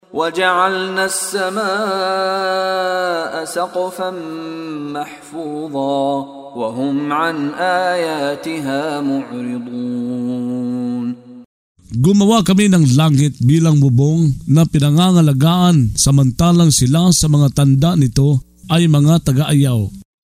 Pagbabasa ng audio sa Filipino (Tagalog) ng mga kahulugan ng Surah Al-Anbiyấ ( Ang Mga Propeta ) na hinati sa mga taludtod, na sinasabayan ng pagbigkas ng reciter na si Mishari bin Rashid Al-Afasy. Ang pagtitibay sa mensahe, ang paglilinaw sa kaisahanng layon ng mga propeta, at ang pangangalaga ni Allāhsa kanila